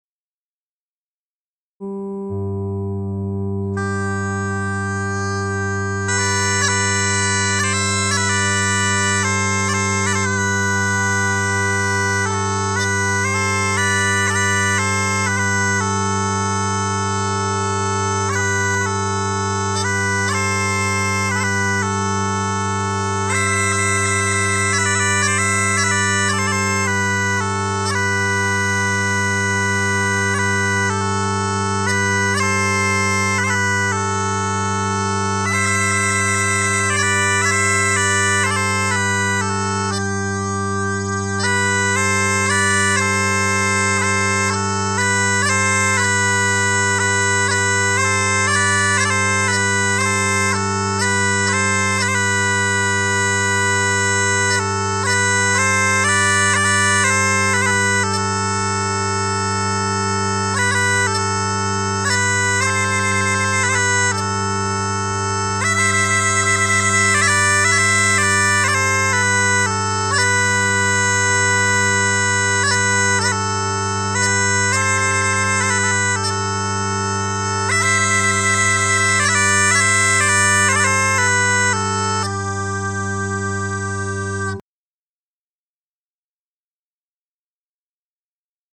Immagine dei primi  anni del '900, stereotipata  nella sua classica semplicità: gli zampognari.
<< L'arrivo degli zampognari dai monti, a metà Dicembre, era, e sia pure in misura minore lo è ancora, un particolarissimo annuncio  sonoro dell'imminente periodo Natalizio. ... Un suono da "organo dei poveri"  che Pascoli definisce con termini quanto mai felici ... >>.
zampognari.mp3